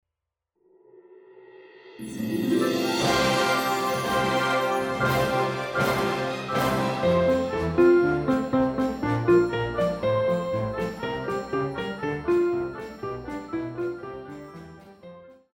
流行
鋼琴
管弦樂團
鋼琴曲,演奏曲
世界音樂
獨奏與伴奏
有主奏
有節拍器